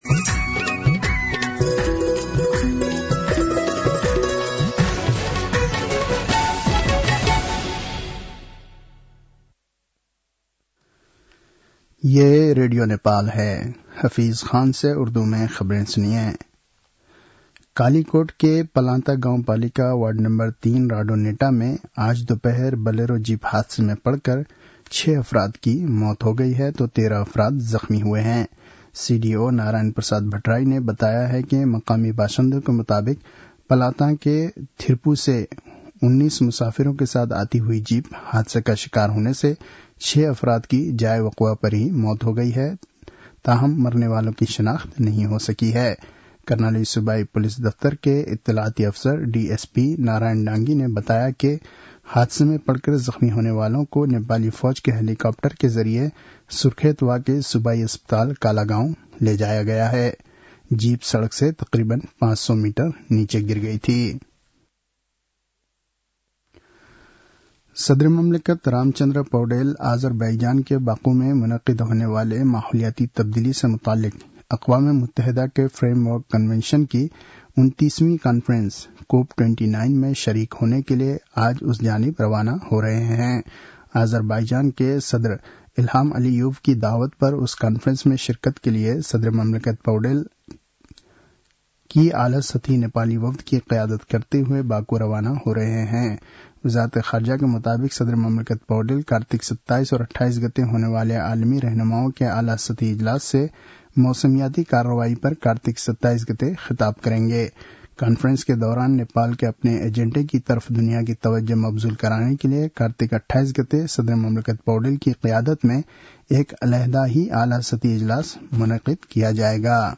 उर्दु भाषामा समाचार : २६ कार्तिक , २०८१